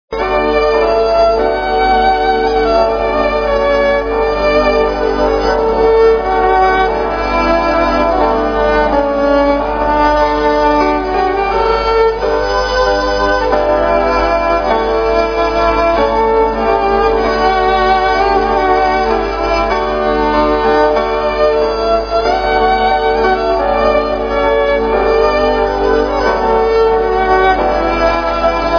(Live in Belfast)